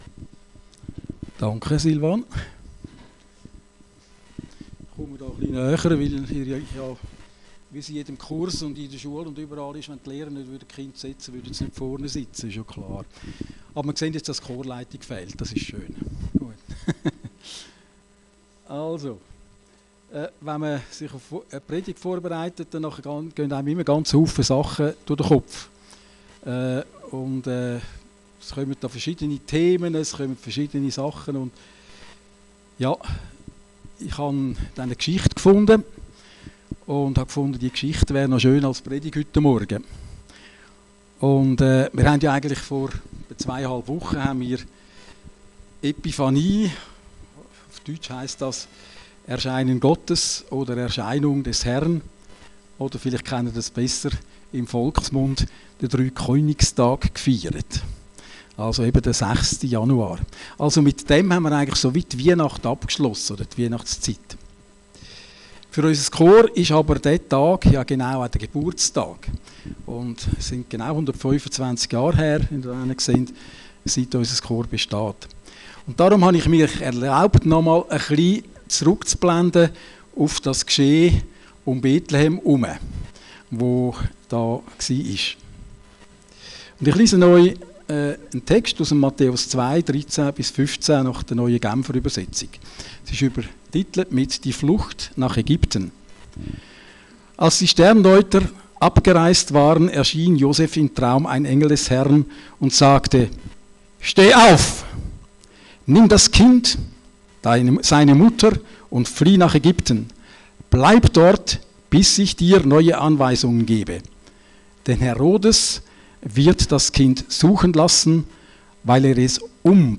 Predigten Heilsarmee Aargau Süd – Oh ich Esel! ..oder wie mich der störrische Esel inspiriert